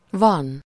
Ääntäminen
France (Paris): IPA: [a.vwaʁ]